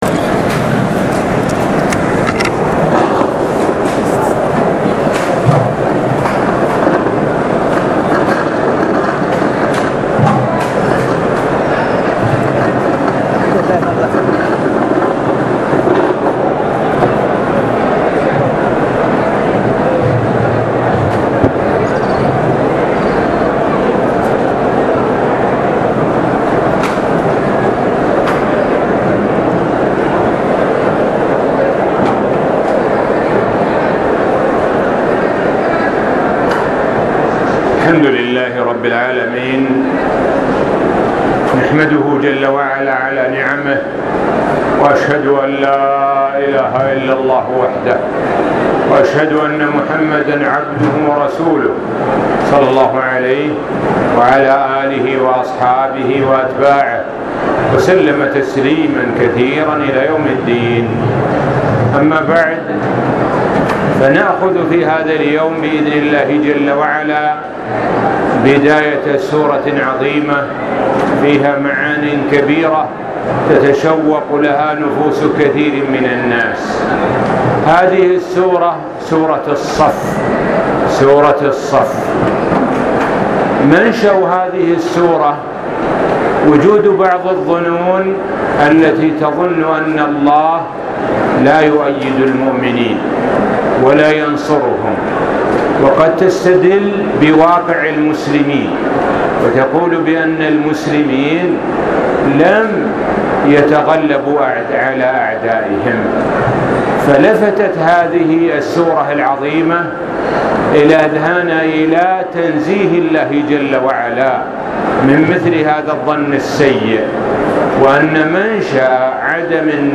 الدرس--14 الصف [1-4] السابق التالى play pause stop mute unmute max volume